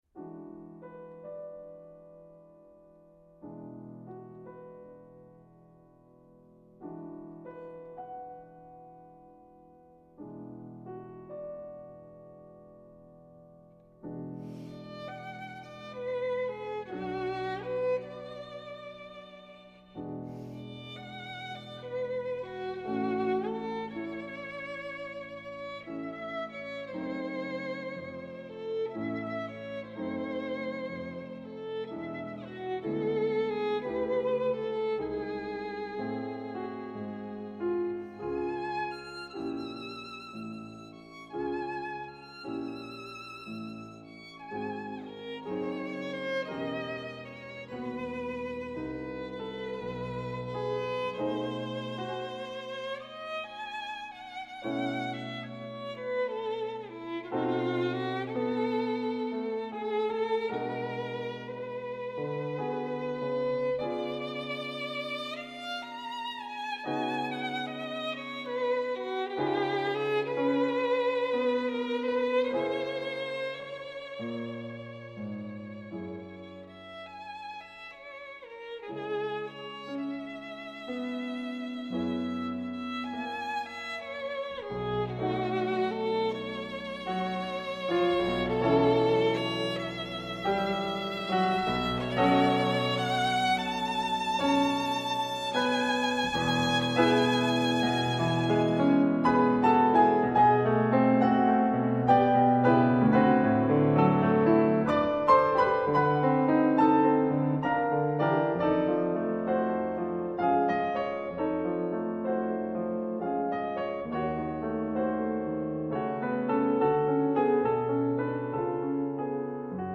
Incontro con Bruno Canino